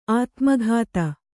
♪ ātmaghāta